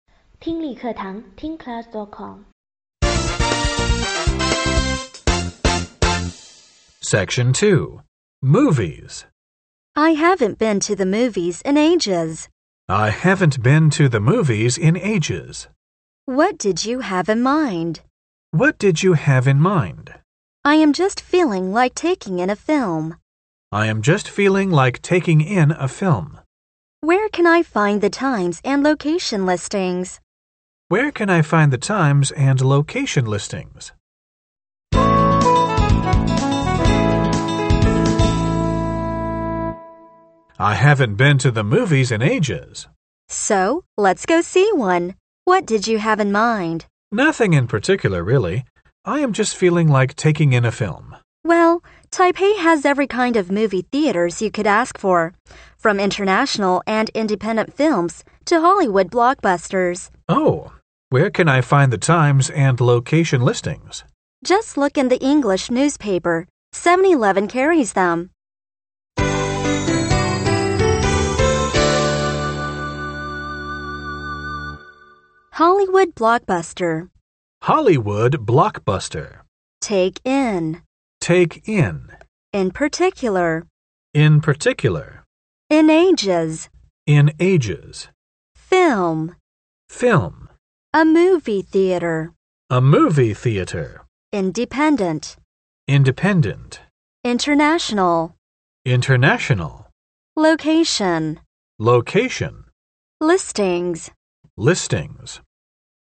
本集英语情境会话